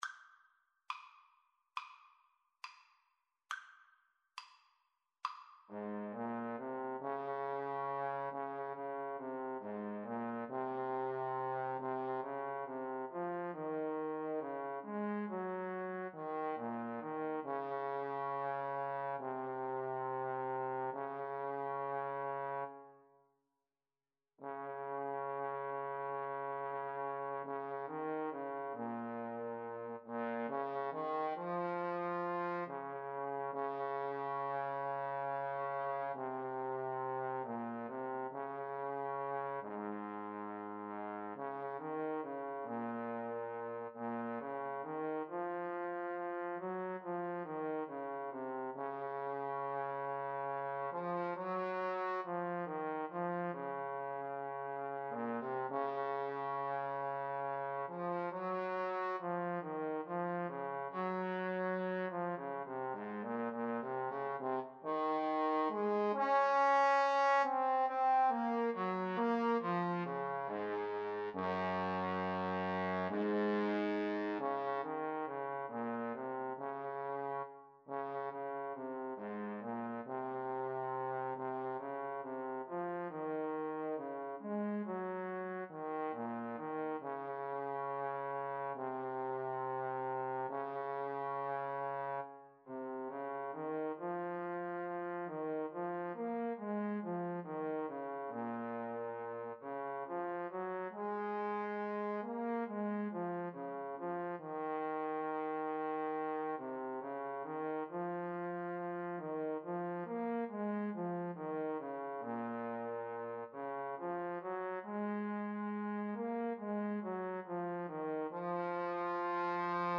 4/4 (View more 4/4 Music)
Espressivo = c. 69
Classical (View more Classical Trombone Duet Music)